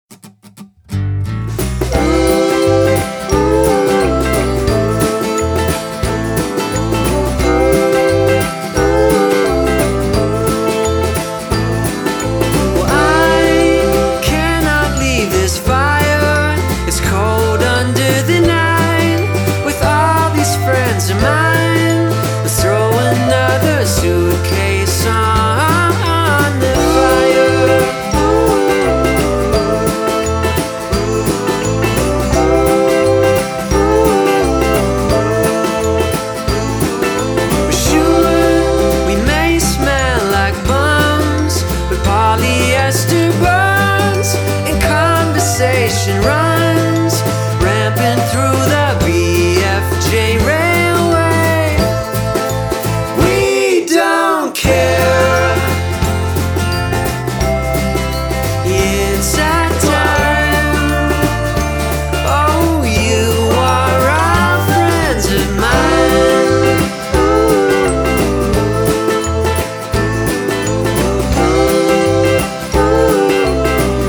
local rock band
a bittersweet little pop song with harmonizing vocals